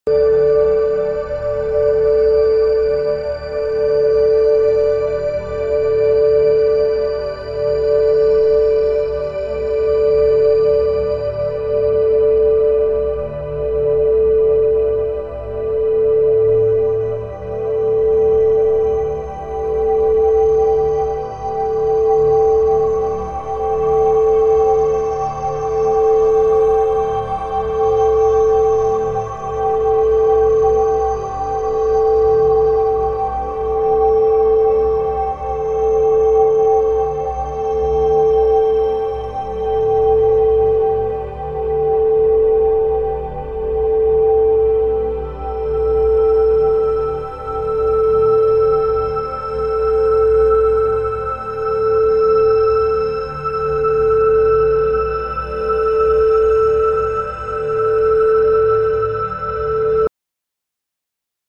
Binaural Beats Frequency of sound effects free download
Binaural Beats - Frequency of love Spiritual healing and self-knowledge